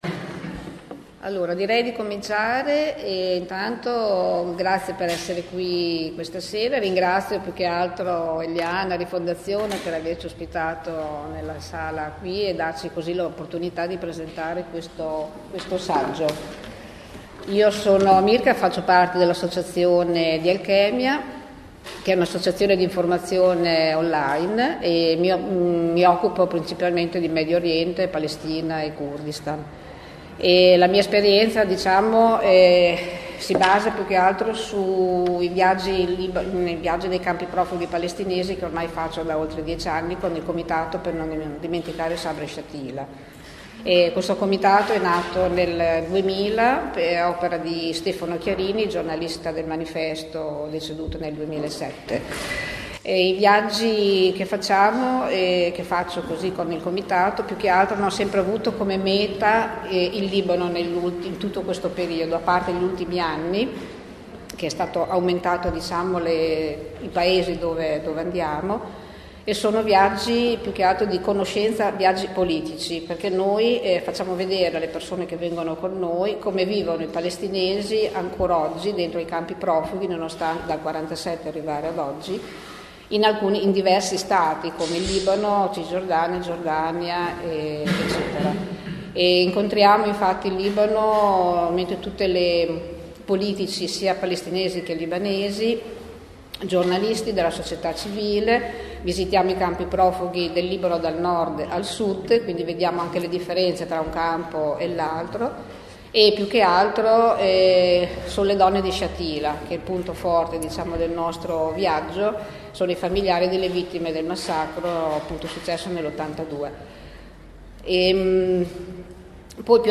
Le domande e il confronto con il Pubblico